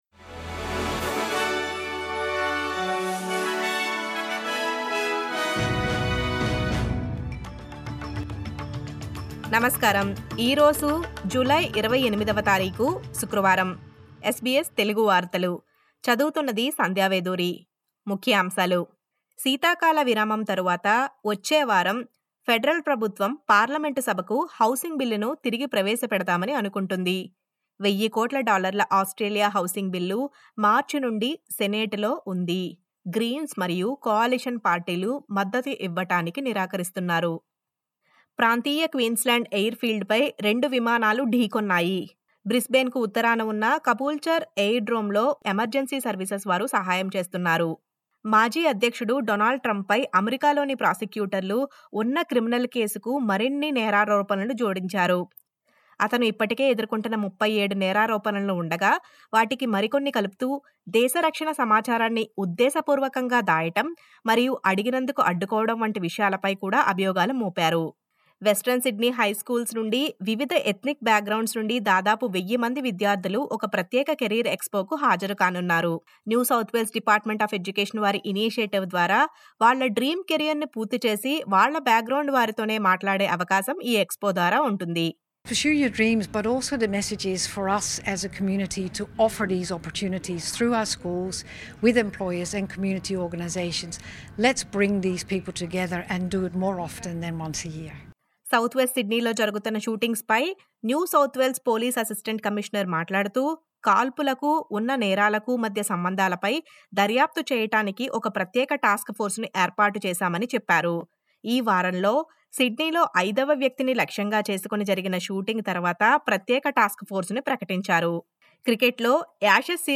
SBS Telugu వార్తలు